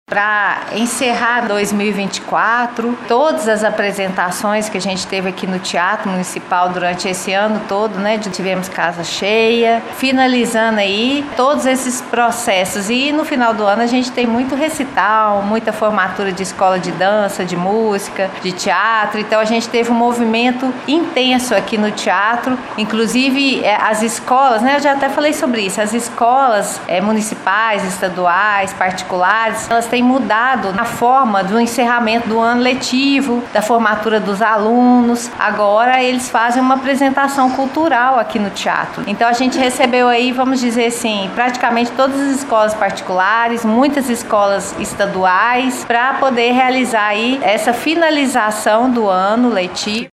Em conversa com o JM